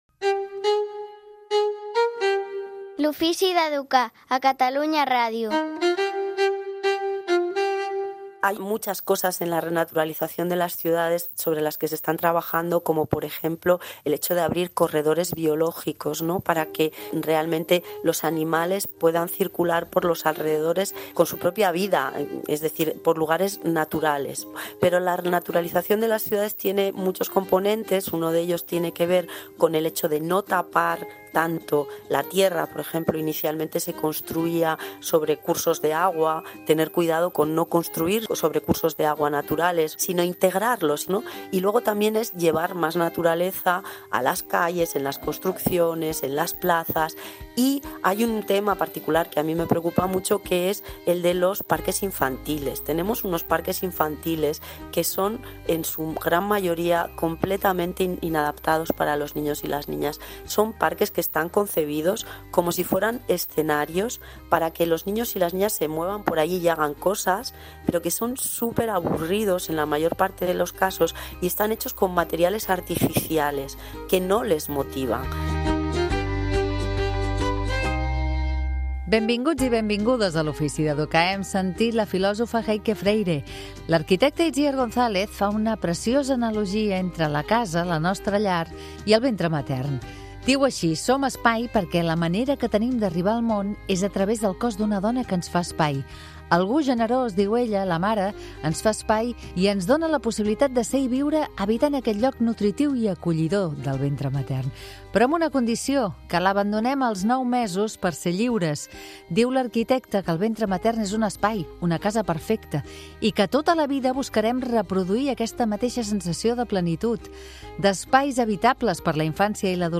En parlem a "L'ofici d'educar", amb Itziar González, veïna, urbanista, alertadora, decreixentista, activista i presidenta de l'Observatori Ciutadà contra la Corrupció.